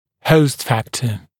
[həust ‘fæktə][хоуст ‘фэктэ]фактор отторжения организмом инородного тела (напр. при установке имплантатов)